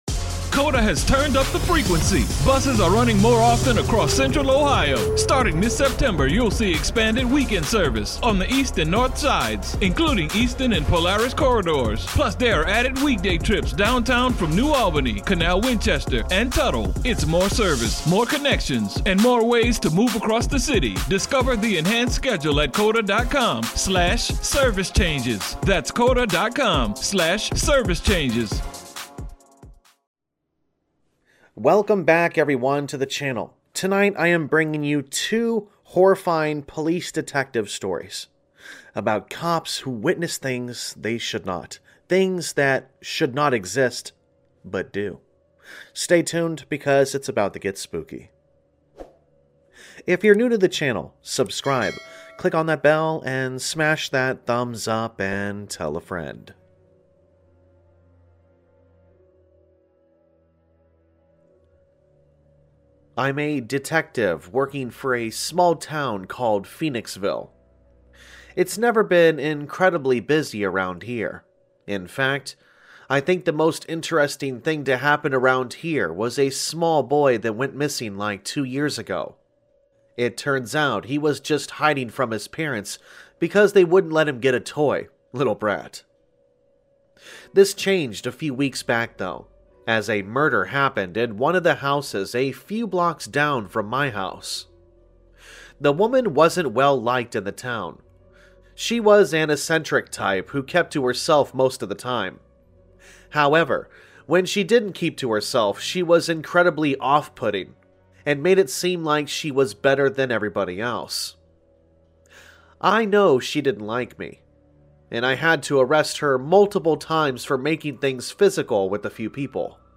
Sound Effects Credits
All Stories are read with full permission from the authors